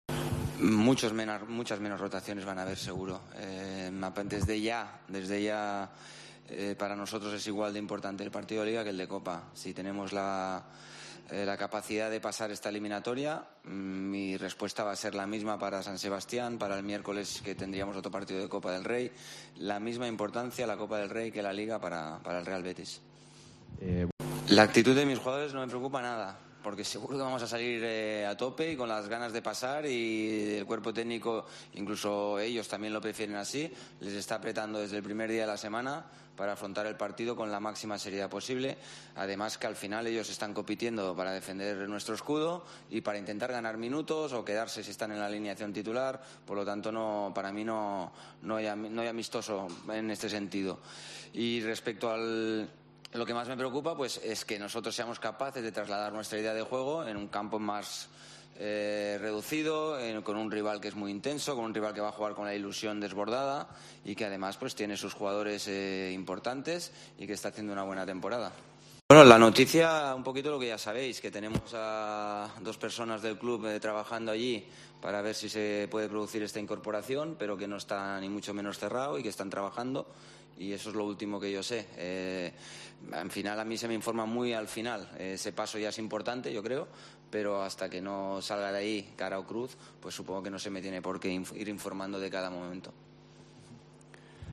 Rubi, en la rueda de prensa previa al partido ante el Portugalete